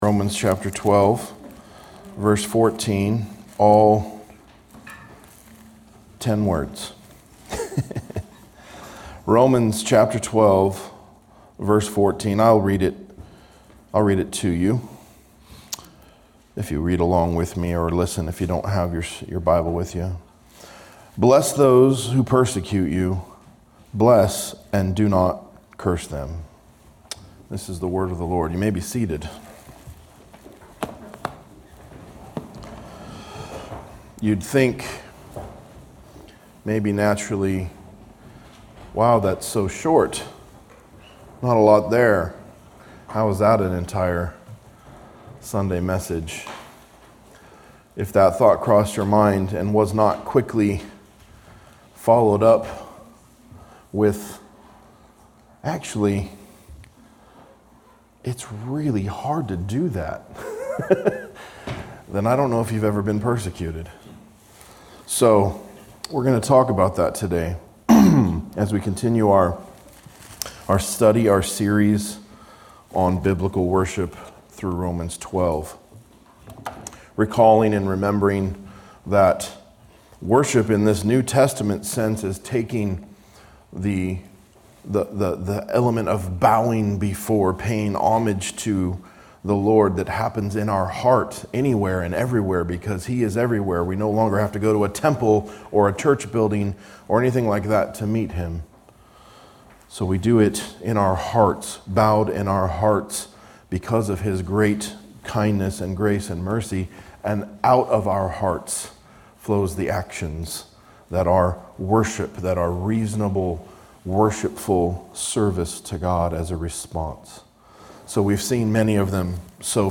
A message from the series "Biblical Worship Series." We worship God outwardly by responding to persecution with gospel grace.